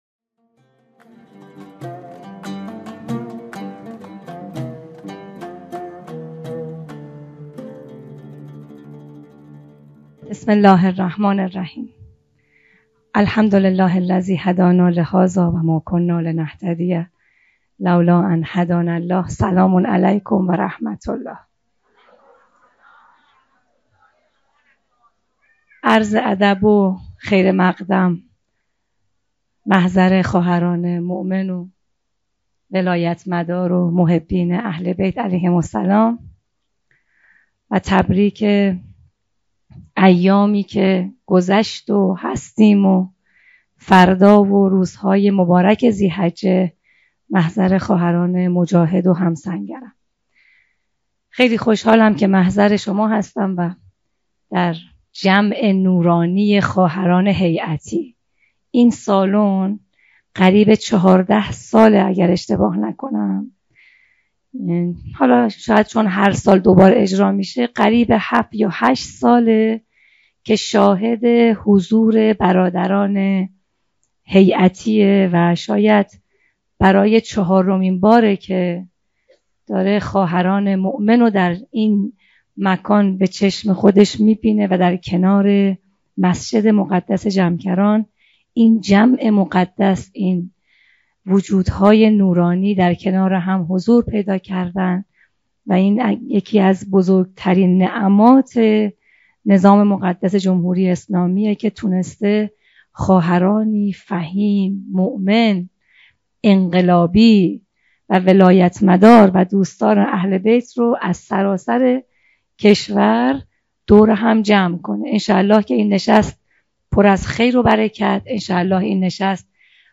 آخرین وضعیت جبهه فرهنگی بانوان | چهارمین همایش بانوان فعال در عرصه هیأت | شهر مقدس قم - جمکران - مجتمع یاوران مهدی (عج)